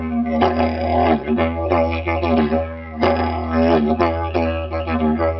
迪吉里杜管7
用AudioTechnica Pro 70迷你话筒录制。 17年的巨型木制竹迪吉里杜管（由我制作）。
Tag: 90 bpm Ethnic Loops Didgeridoo Loops 931.17 KB wav Key : Unknown